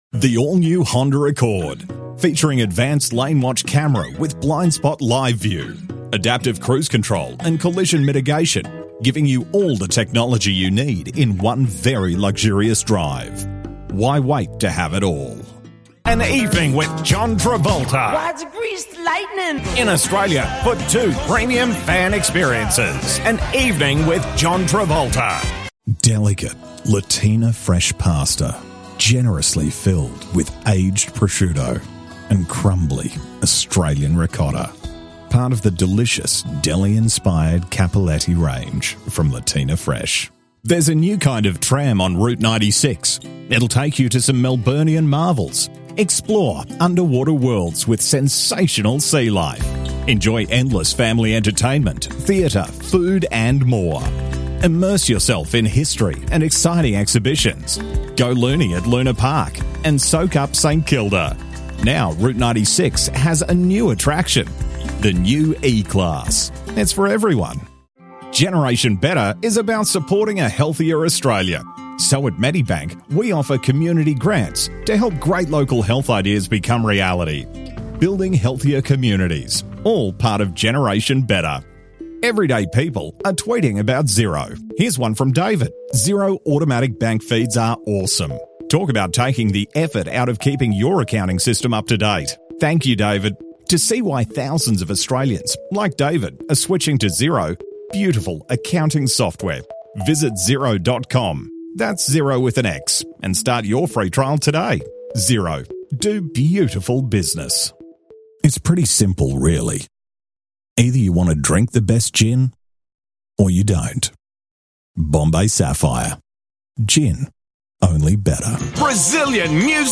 Anglais (Australien)
Voix australienne expérimentée et familière avec une portée, une polyvalence et une passion pour l'esprit et l'humour, et le se...
De la conversation
Autoritaire